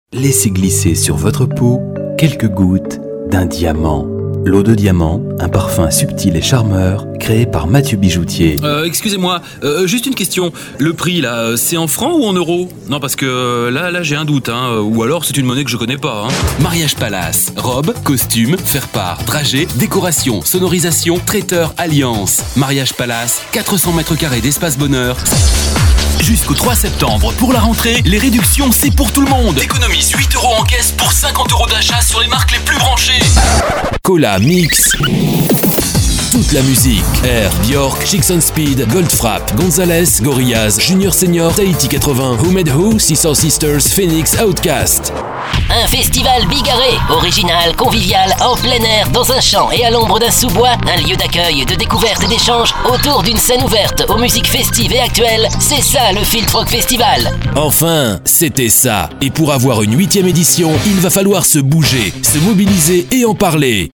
voix medium, dynamique, posée, jouée pour publicités ou commentaires, e learning, attentes téléphoniques ou tout autre travail audio station protools micro B1
Sprechprobe: Werbung (Muttersprache):